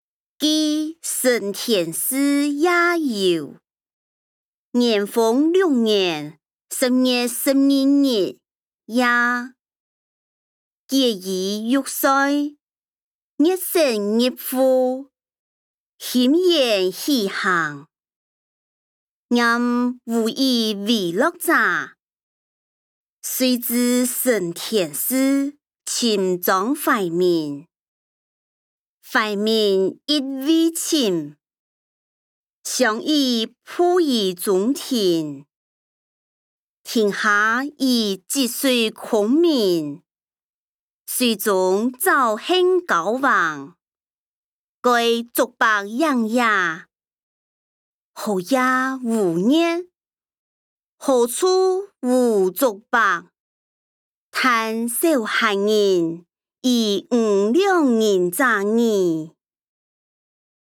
歷代散文-記承天寺夜遊音檔(四縣腔)